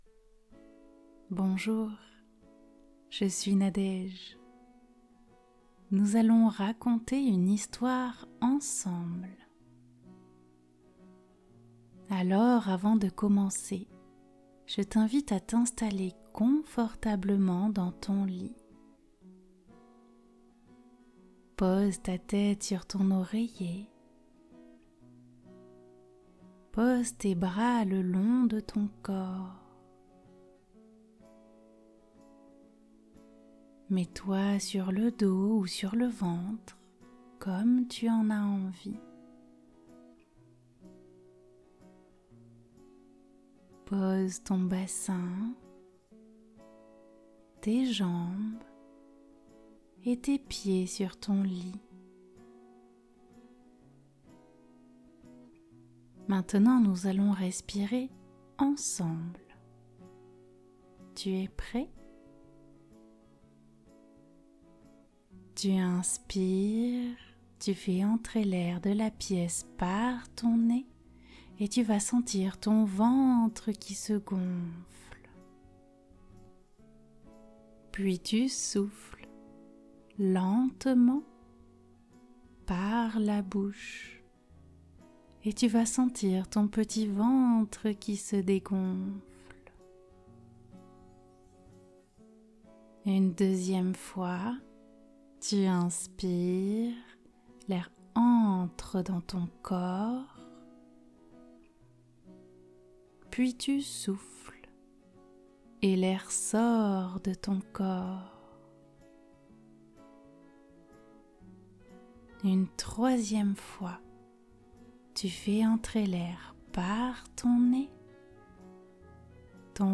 Découvrez des enregistrements audios pour la relaxation et l'apaisement des enfants.
Histoires-relaxantes-le-bebe-mille-pattes-OK.mp3